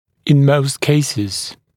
[ɪn məust ‘keɪsɪz][ин моуст ‘кейсиз]в большинстве случаев